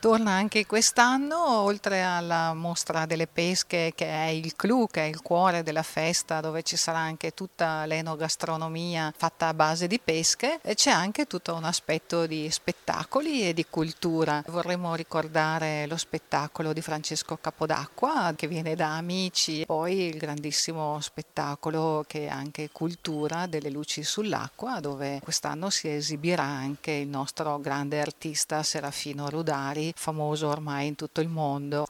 La “Festa d’Estate” di Bussolengo, da venerdì 18 a martedì 22 agosto, prevede un intenso programma, cinque giorni di manifestazioni e spettacoli tra Piazza XXVI Aprile, Corso Mazzini e Villa Spinola, e di graditi ospiti, come ci ha raccontato il sindaco della città, Paola Boscaini:
sindaco-Paola-Boscaini.mp3